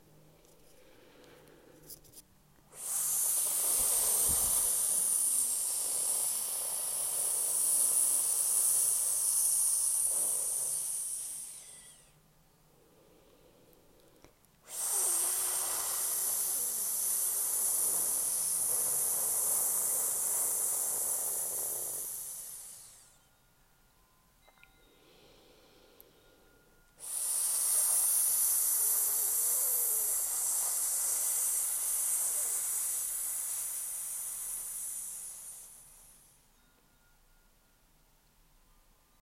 Fourth Sound: SZZ
Similarity: like making a hissing sound